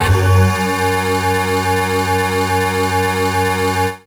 55bg-syn09-f#2.wav